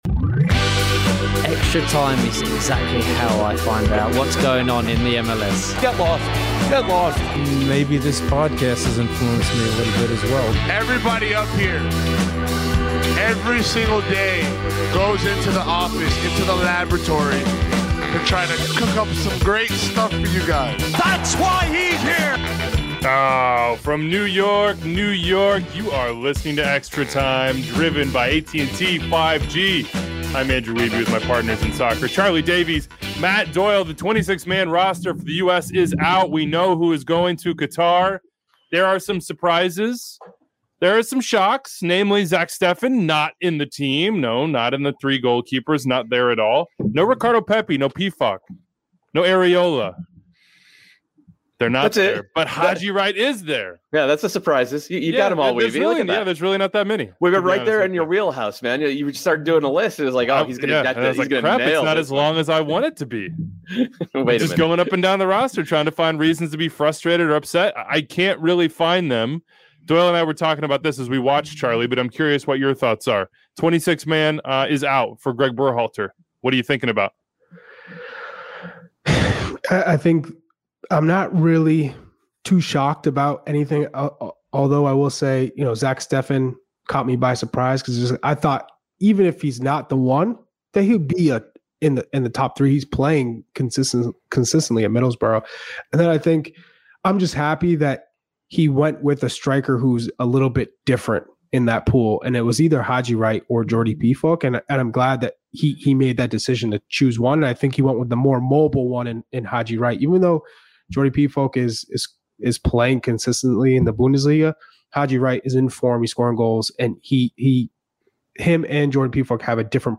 jumped in the studio for an emergency podcast! Reactions to Steffen and Pepi missing out, plus first look at Wales!